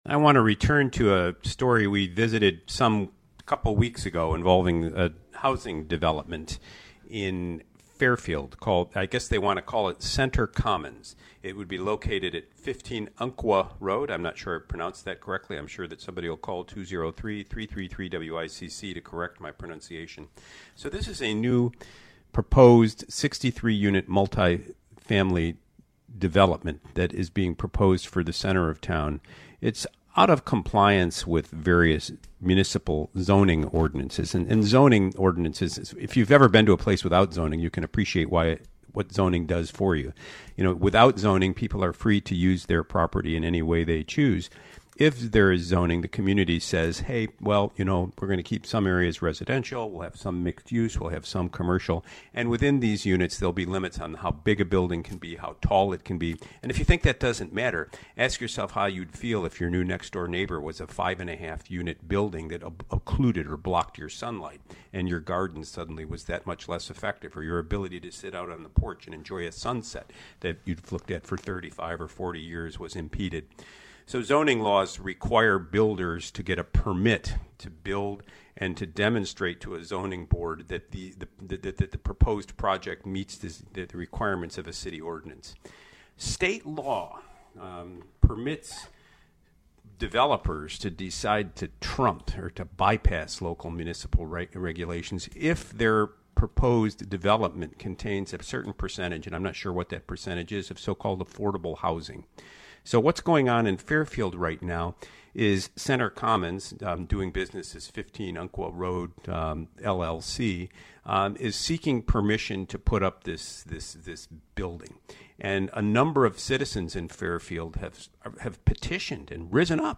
Naturally, this brought out some of the callers to contribute to the conversation.